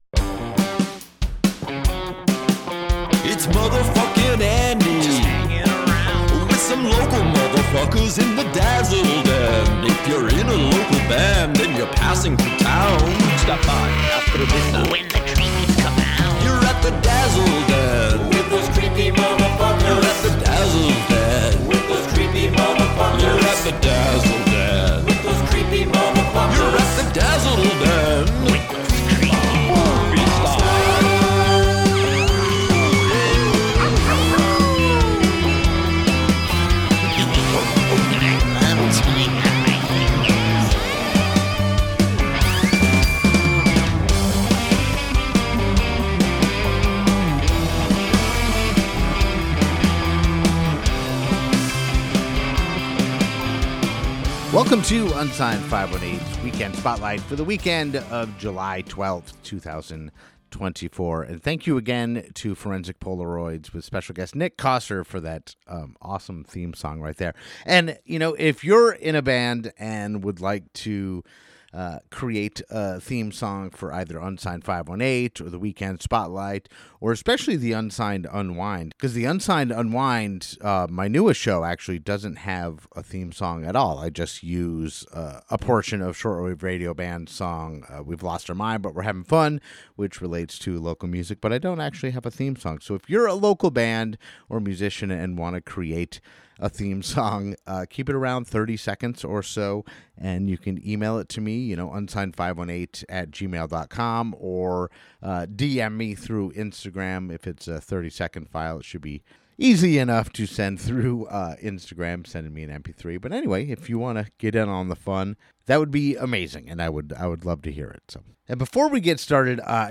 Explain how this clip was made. After taking a week off for the holiday, Unsigned518's Weekend Spotlight is back! On this episode, I spotlight two local shows coming up this weekend, and play a song from one local artist off each lineup.